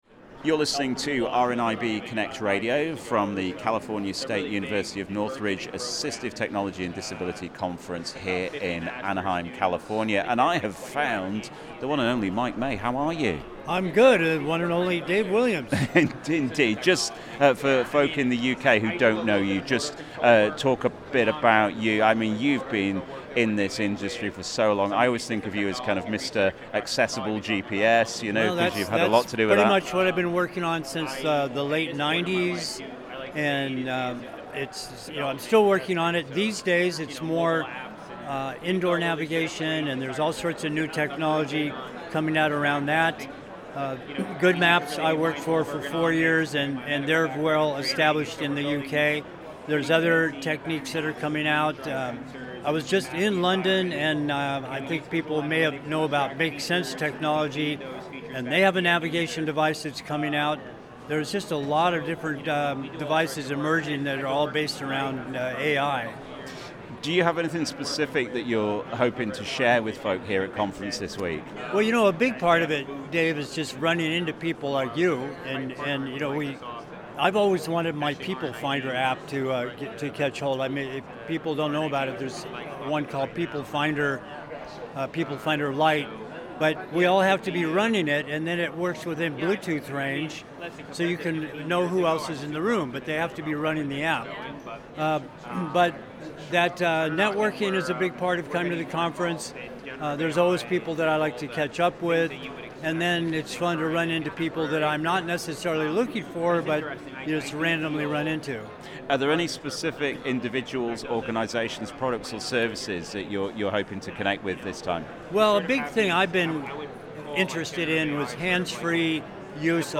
has been on the conference floor this year to speak to some of the delegates